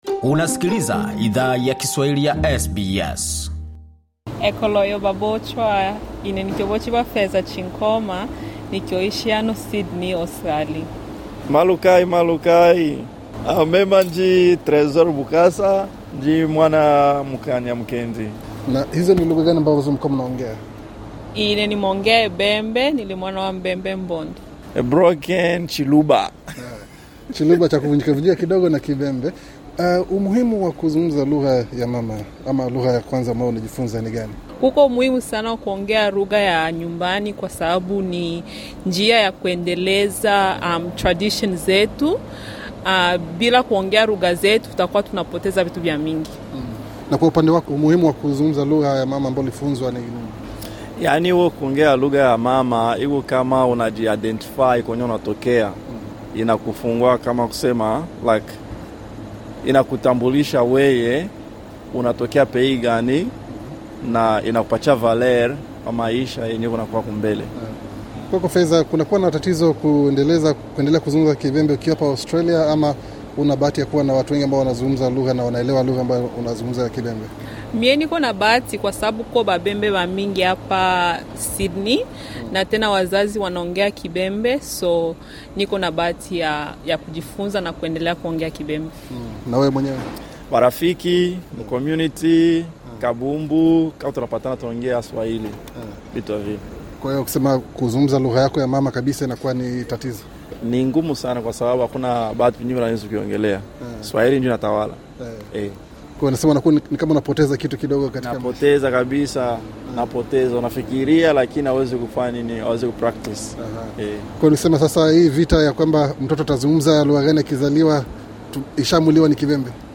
SBS Swahili ili zungumza na baadhi ya wanachama wa jumuiya kutoka Afrika Mashariki ambao walifunguka kuhusu umuhimu waku ongea lugha ya mama, haswa nchini Australia. Bonyeza hapo juu kwa mahojiano kamili.